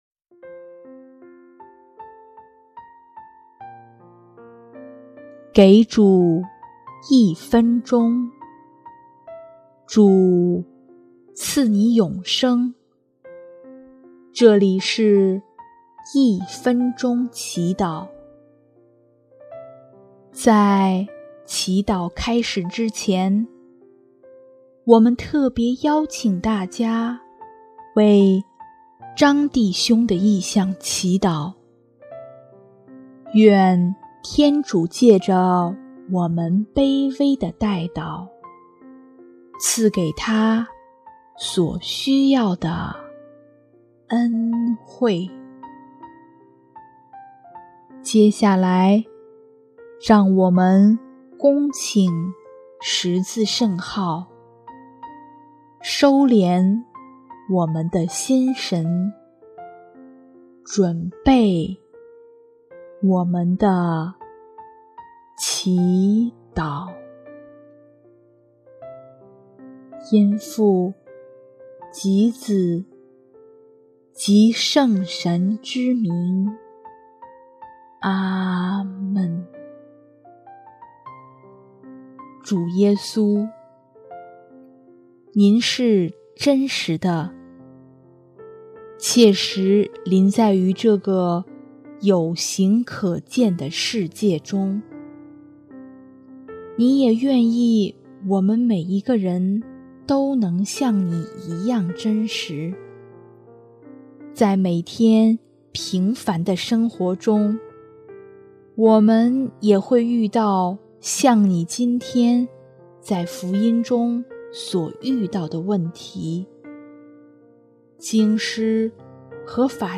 【一分钟祈祷】| 6月3日 在真实与自由内活出自己